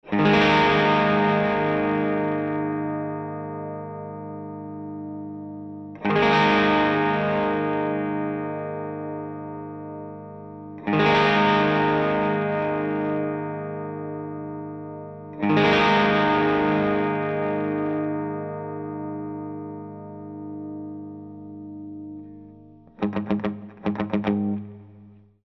There is a weird bloom of fizz as the chords ring out - please listen to the attached mp3 clip.